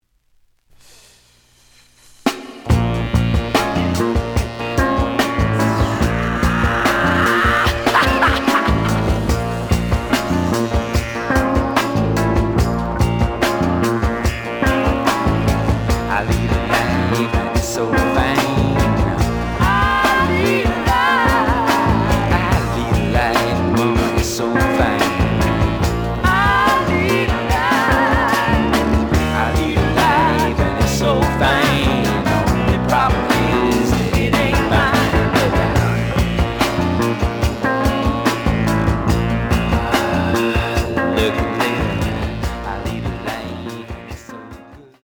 The audio sample is recorded from the actual item.
●Format: 7 inch
●Genre: Jazz Rock / Fusion